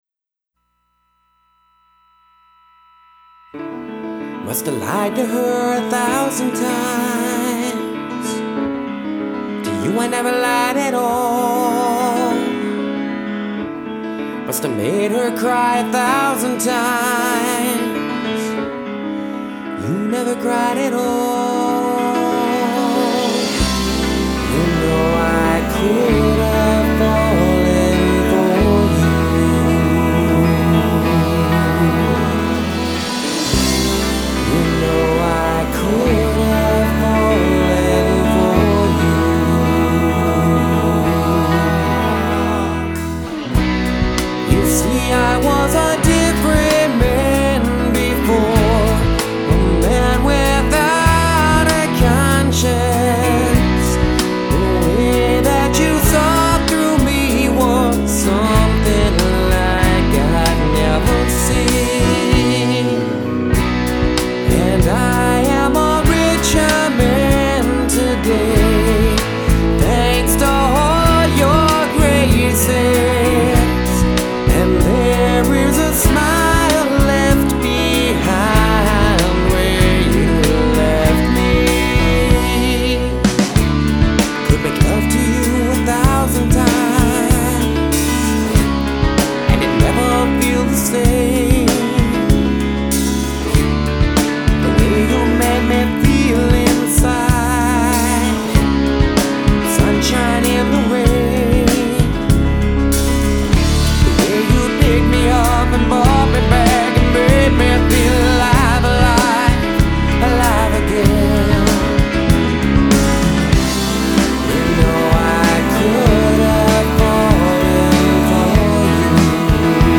Genre: Alternative Piano Power Pop.